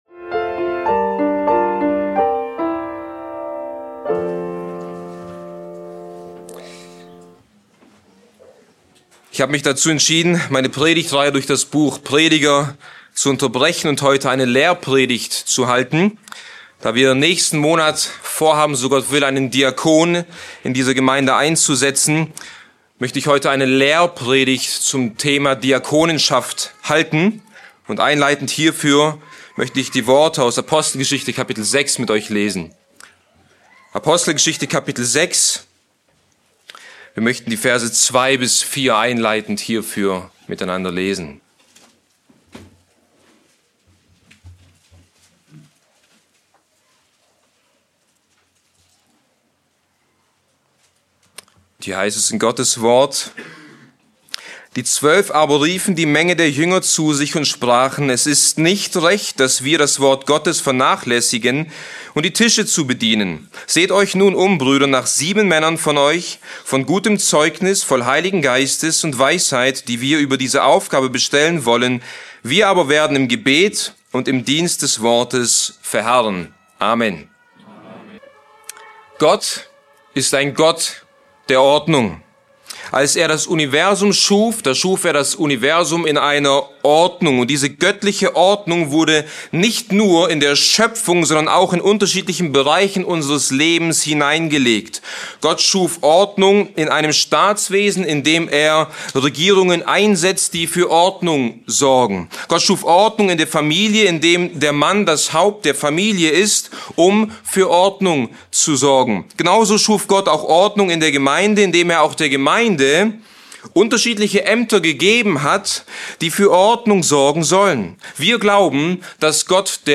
Beschreibung vor 2 Monaten Die Lehrpredigt erläutert das biblische Amt des Diakons, betont dessen Qualifikationen und Aufgaben, um die Gemeinde und Älteste zu unterstützen und Gottes Herz für Bedürftige widerzuspiegeln.